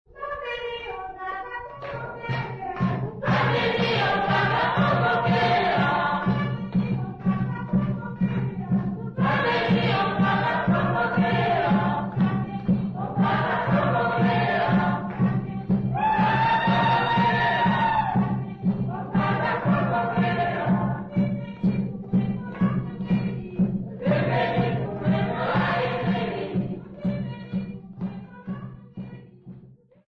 Tondoro church music workshop participants
Sacred music Namibia
Choral music Namibia
Ngoma (Drum) Namibia
Africa Namibia Tondoro, Okavango sx
field recordings
Church song with drum and clapping accompaniment.